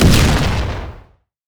poly_explosion_rocket.wav